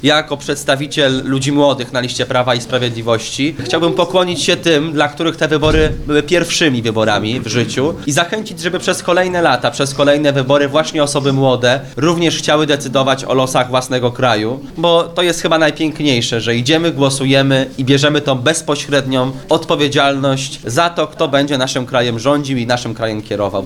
Cieszymy się ze zwycięstwa, zwyciężyliśmy i dziękujemy wszystkim wyborcom za głos – mówi Anna Kwiecień, posłanka PiS.